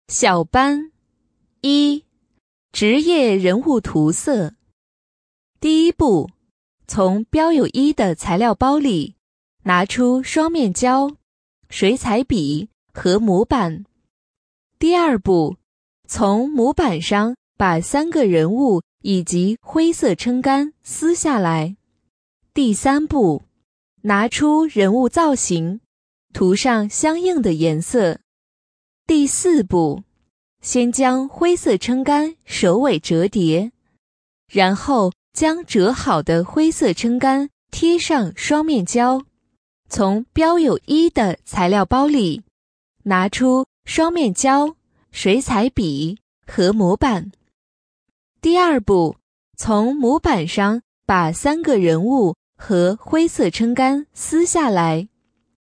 【女57号课件解说】儿童教学